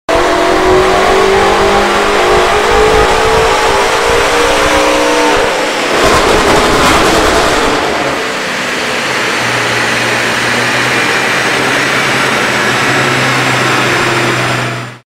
Every pull is German engineering at full throttle.